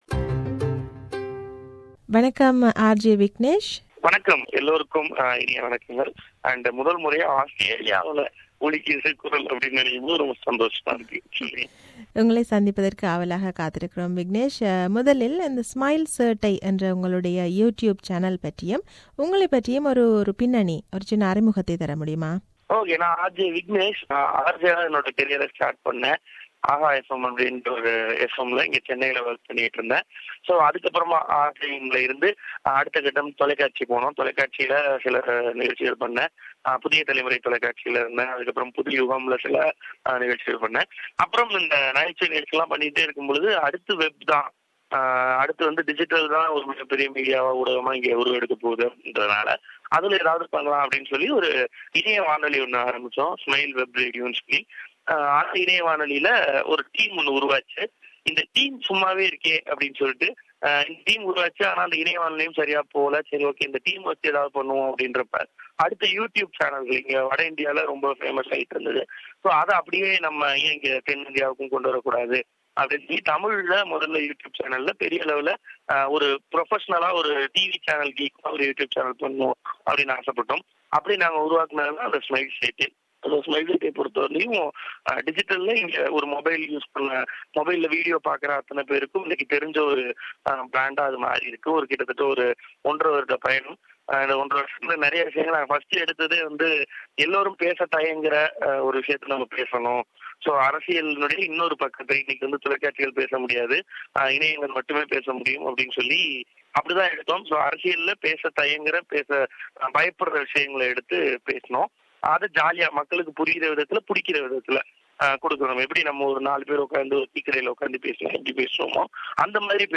Thillana - 7 is an annual show conducted by Sydney Tamil Manram its stepping into its 7th year in grand fashion. The young and energetic team Smile Settai is stepping onto Sydney shores with their informative and funny content. This is an interview